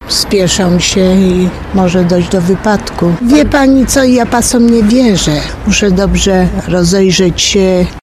Przy przechodzeniu przez jezdnię,  ważna jest zasada ograniczonego zaufania, twierdzą piesi.